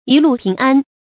一路平安 注音： ㄧˊ ㄌㄨˋ ㄆㄧㄥˊ ㄢ 讀音讀法： 意思解釋： 旅途上平安順利。